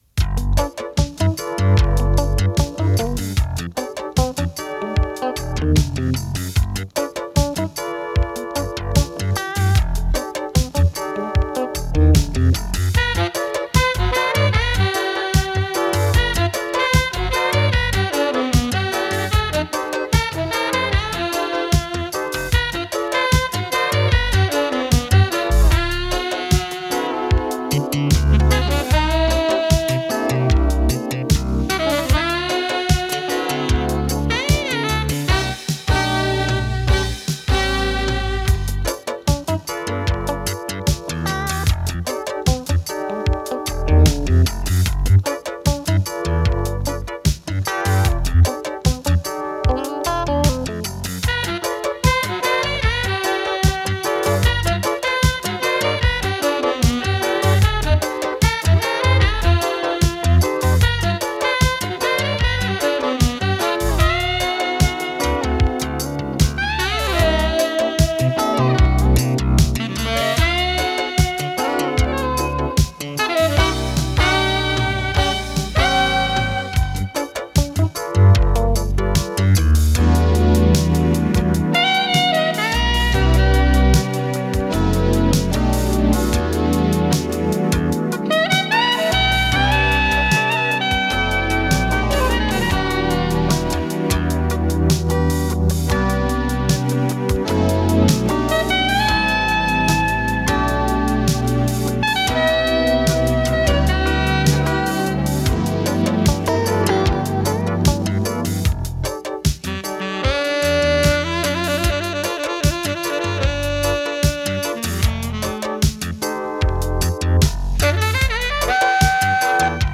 lead synthesizer.
synthesizer accompaniment, synth-bass and drum sequencing.
electric guitar and sound effects.
saxophone. Recorded April 3rd, 1986